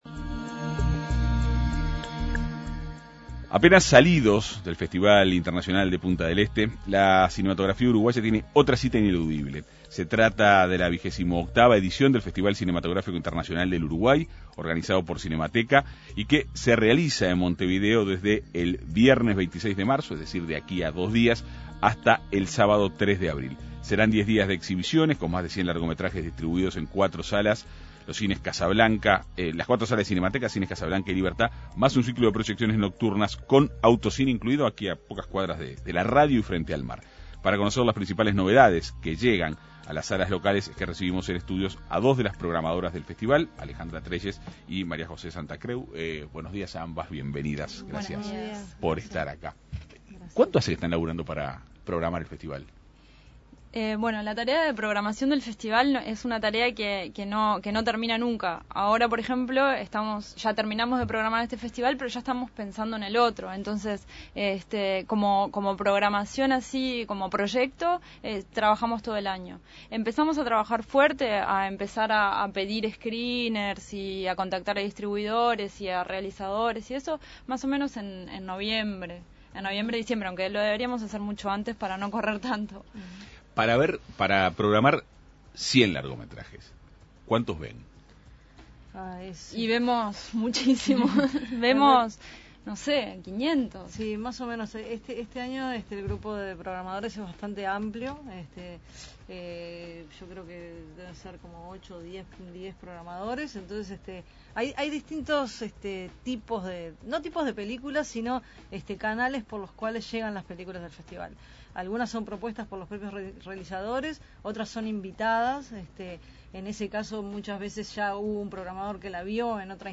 Se trata de la vigésimo octava edición del Festival Cinematográfico Internacional del Uruguay, organizado por Cinemateca, que se realizará en Montevideo desde el viernes 26 de marzo hasta el sábado 3 de abril. Serán 10 días de exhibiciones con más de 100 largometrajes, distribuidos en las cuatro salas de Cinemateca, los cines Casablanca y Libertad más un ciclo de proyecciones nocturnas con autocine incluido. Para conocer detalles del evento, En Perspectiva Segunda Mañana dialogó con dos de las programadoras del Festival